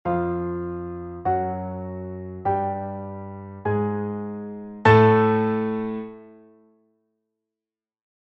Dezember 2019 Gisis (1882) Gisis , das durch x [ Doppelkreuz ] doppelt erhöhte G .
der-Ton-Gisis.mp3